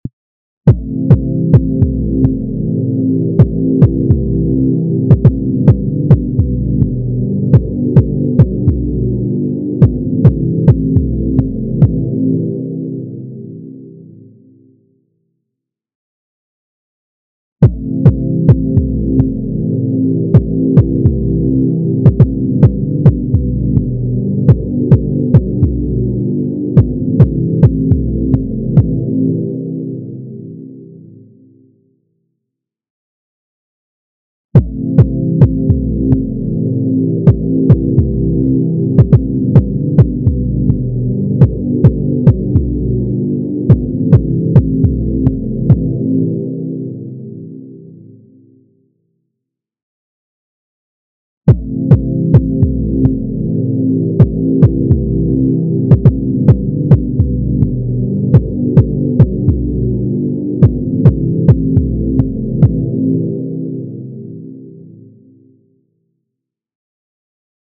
Cut Low Frequency 265Hz from Other.mp3